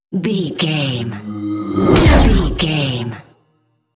Creature sci fi shot appear
Sound Effects
Atonal
tension
ominous
eerie
whoosh